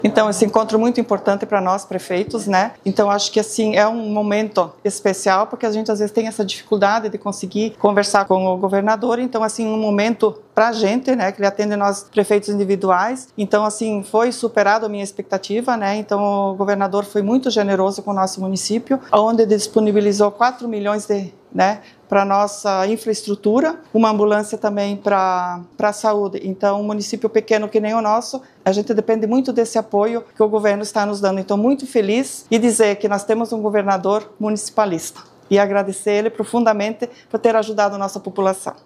Após a conversa individual com o governador Jorginho Mello, a prefeita de Presidente Castelo Branco, Neiva Kleemann Tonielo, destacou o investimento na casa dos R$ 4 milhões para o município: